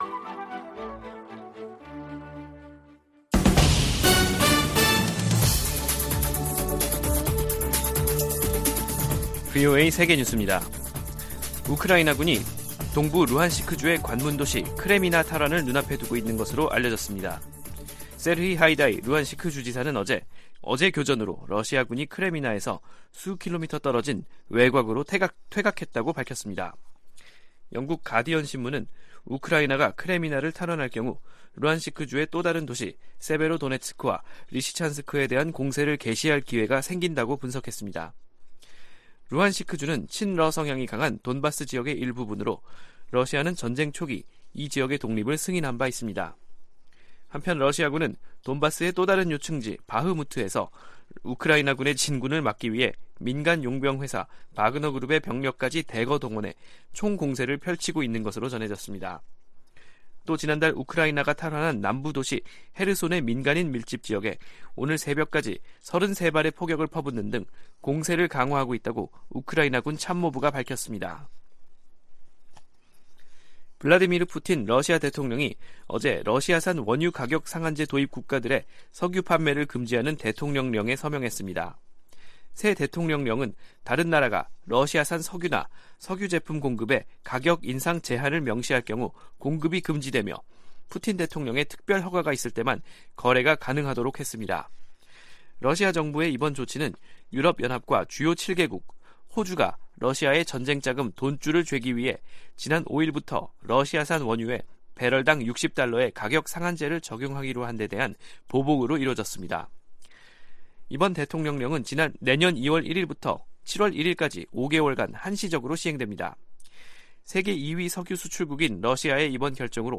VOA 한국어 간판 뉴스 프로그램 '뉴스 투데이', 2022년 12월 28일 2부 방송입니다. 윤석열 한국 대통령은 북한의 드론 즉 무인기 도발을 계기로 강경 대응 의지를 연일 강조하고 있습니다. 유엔은 북한 무인기가 한국 영공을 침범한 것과 관련해 한반도의 긴장 고조에 대해 우려하고 있다는 기존 입장을 재확인했습니다.